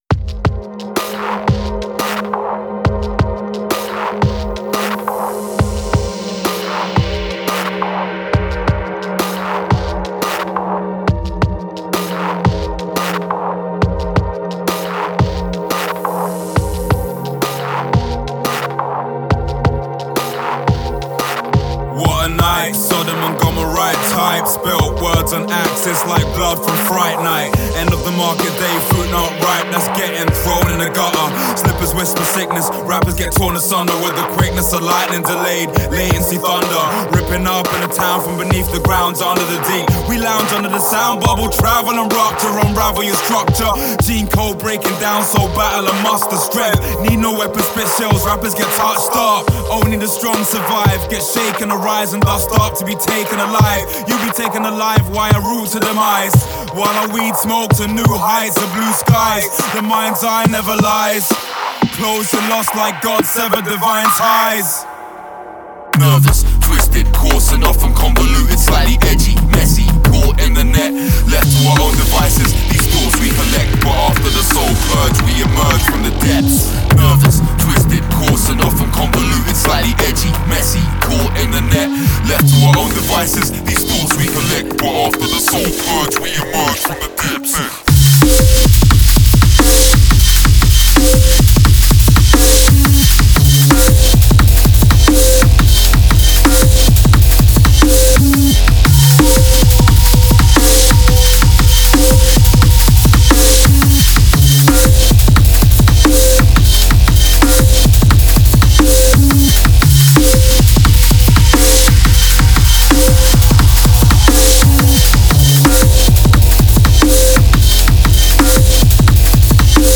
Style: Dubstep, Drum & Bass
Quality: 320 kbps / 44.1KHz / Full Stereo